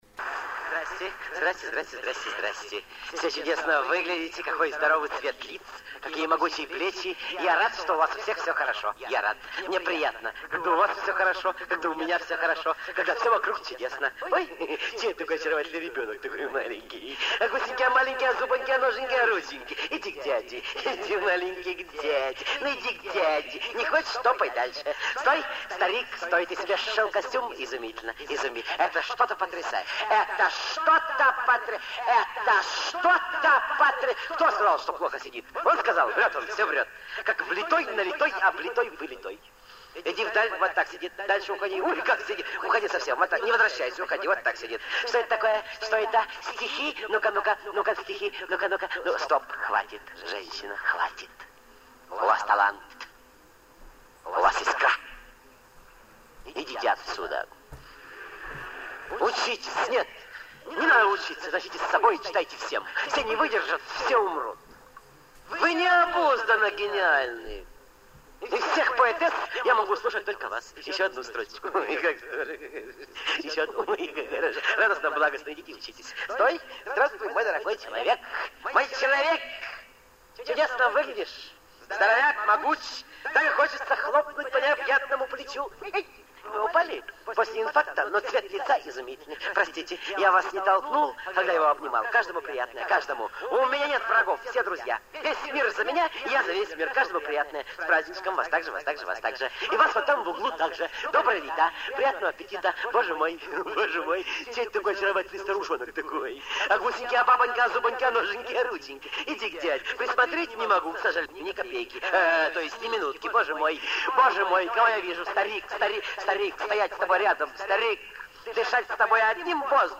Продолжение редких записей миниатюр в исполнении Виктора Ильченко и Романа Карцева. 02 - В.Ильченко-Р.Карцев - Жду звонка